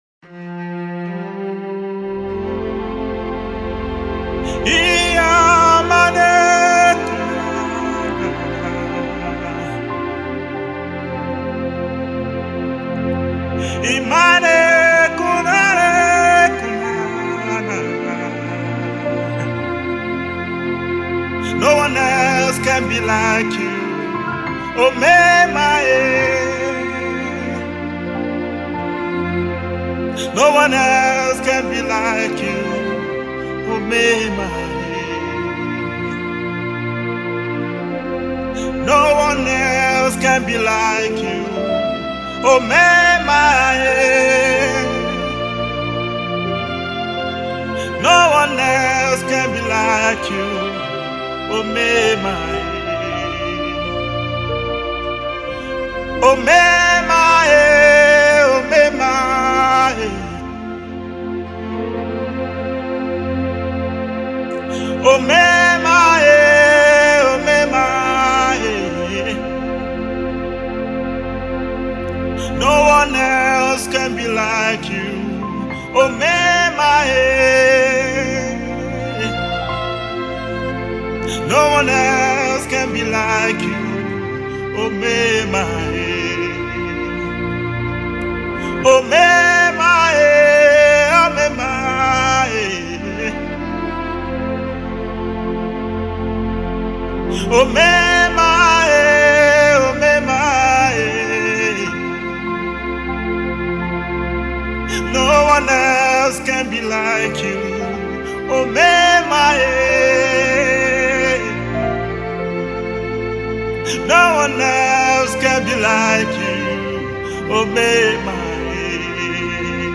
soul-stirring single